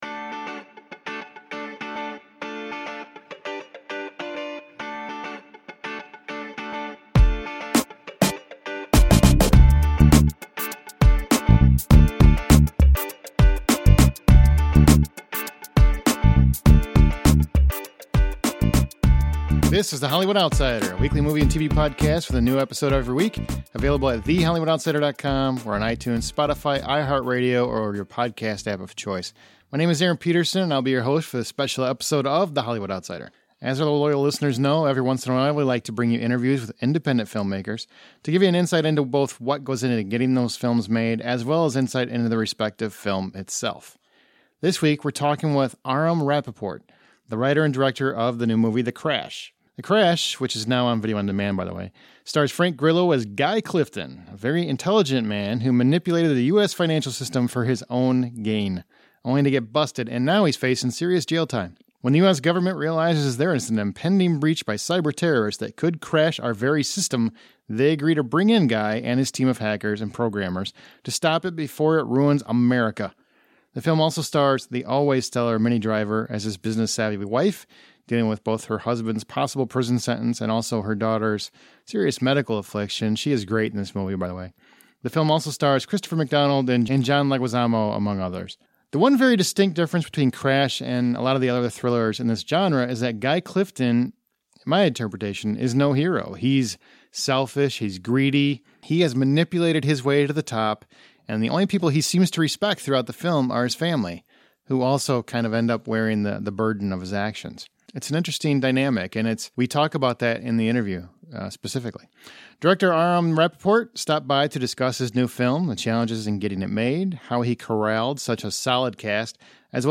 From concept to execution, this is intriguing interview with an up-and-coming director.